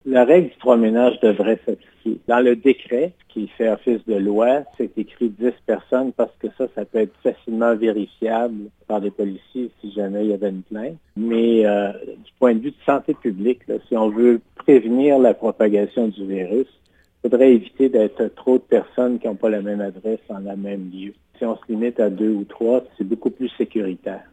D’autre part, même si la permission de rassemblements en résidence privées par décret est limitée à 10 personnes sans autres spécifications, le Dr. Bonnier-Viger recommande de limiter les regroupements à trois ménages différents ou moins :